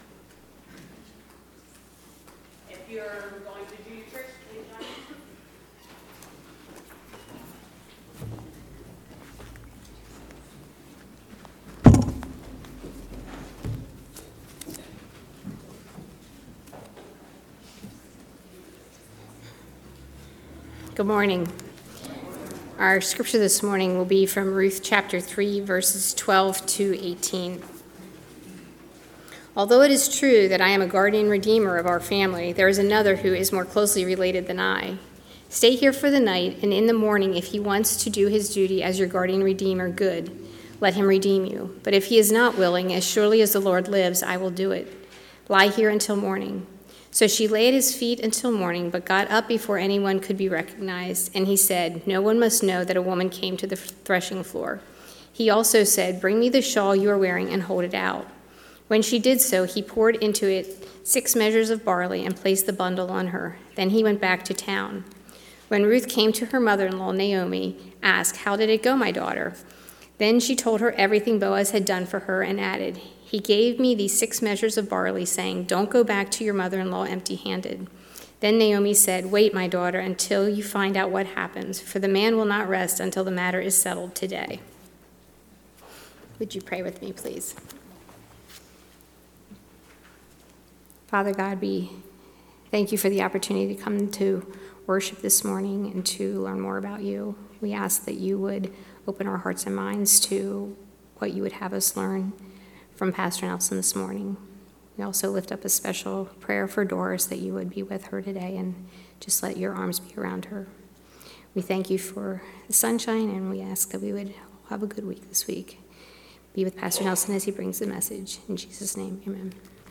A message from the series "August 2025."